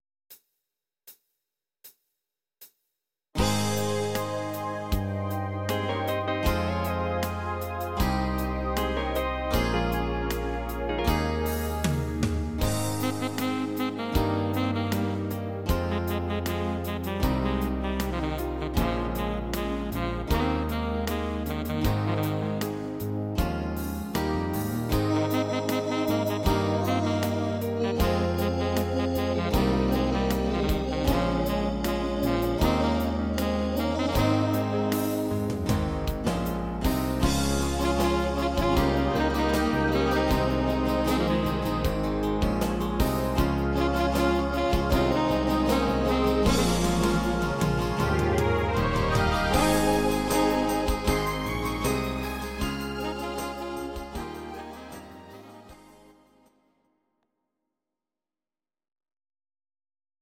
Audio Recordings based on Midi-files
German, Duets, 1980s